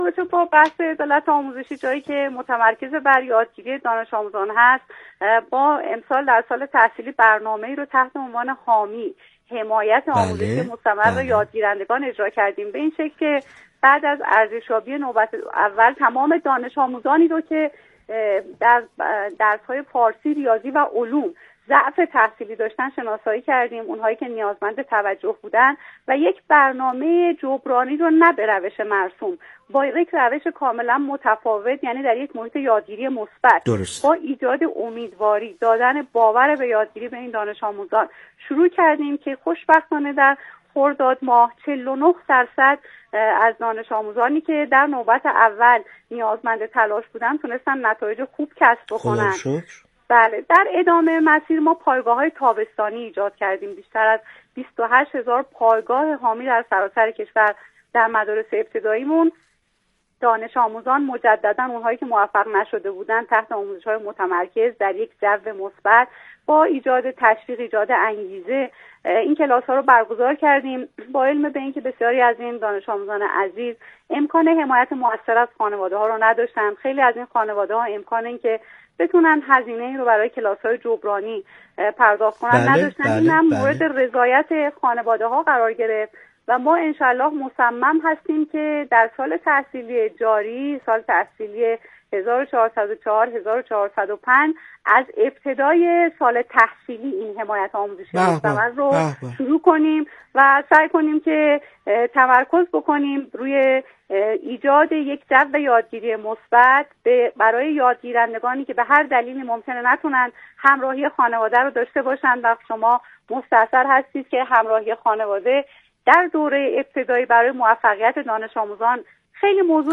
ایکنا به پاس همین اهمیت والا و با درک ژرف از این مسئولیت خطیر، به گفت‌وگو با رضوان حکیم‌زاده، معاون آموزش ابتدایی وزارت آموزش و پرورش نشسته است تا از روایت‌های او در زمینه تحول آموزشی، عدالت محوری و همگامی با نظام آموزش و پروش با فناوری‌های روز در مسیر برداشتن گامی هر چند کوچک در راه اعتلای آموزش و پرورش میهن عزیزمان بگوید و بشنود.